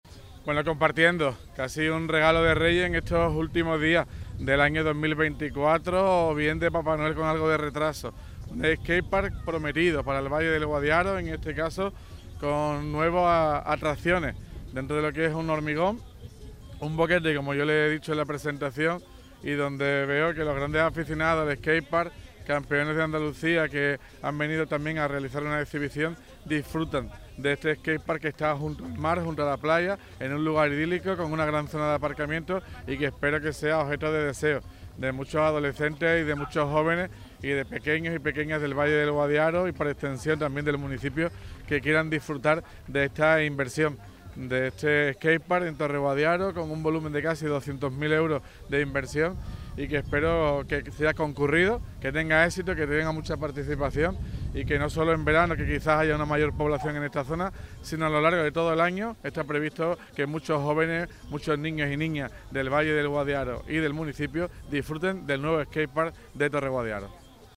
INAUGURACION_SKATE_PARK_TOTAL_ALCALDE.mp3